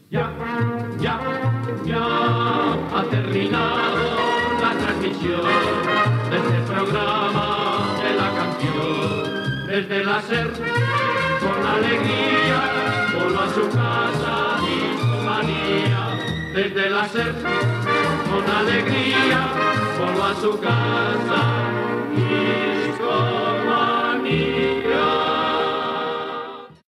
Careta de sortida del programa
Musical